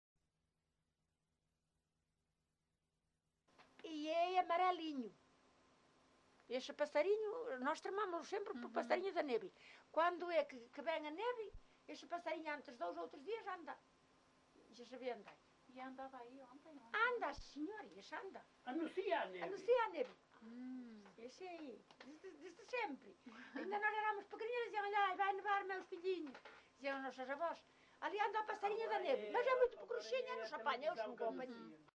LocalidadeCastro Laboreiro (Melgaço, Viana do Castelo)